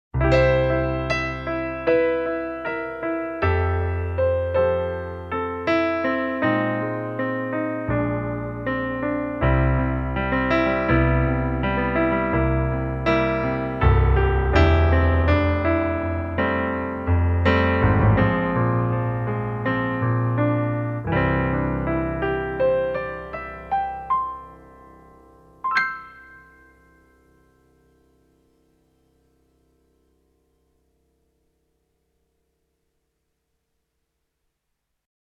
Demo-track played on SK2 with acoustic-piano sound (mp3-file 708KB)
cd-sk-demo-06.mp3